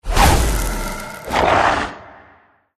GameMpassetsMinigamesCjsnowEn_USDeploySoundGameplaySfx_mg_2013_cjsnow_attacktank.mp3